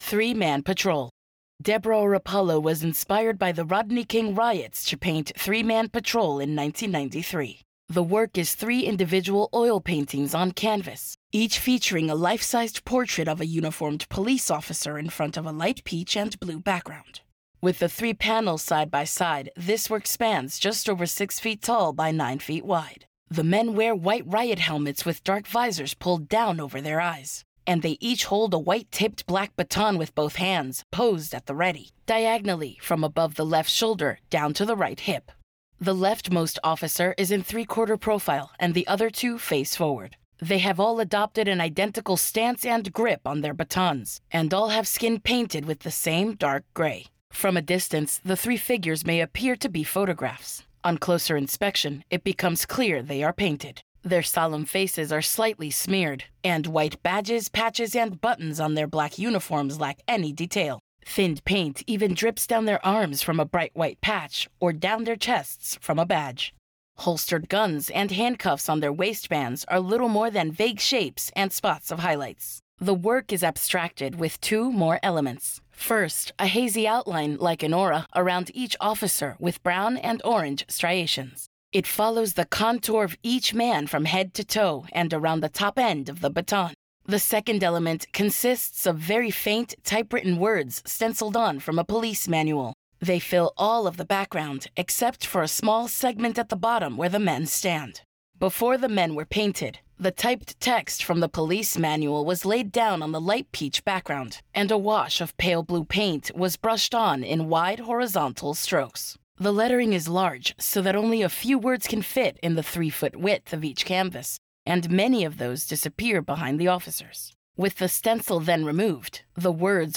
Audio Description (02:21)